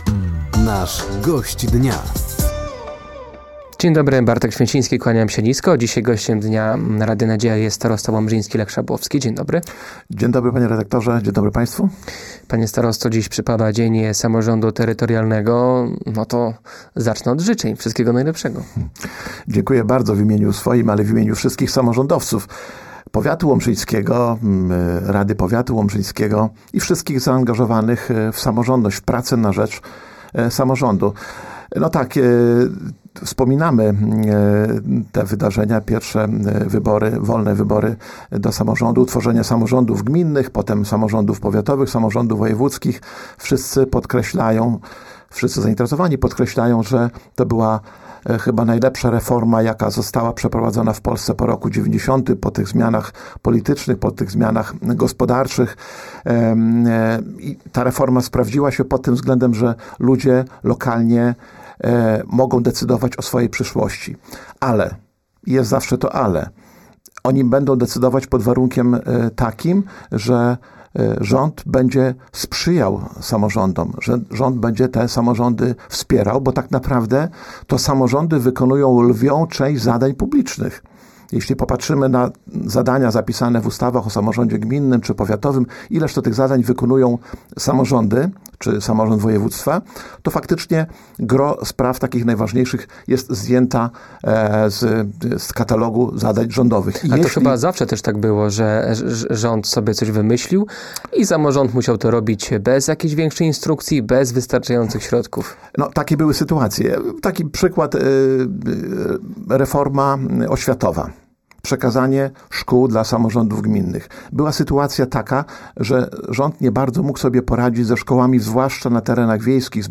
W przypadającym we wtorek (27.05) Dniu Samorządu Terytorialnego gościem Dnia na antenie Radia Nadzieja był Lech Szabłowski, starosta łomżyński.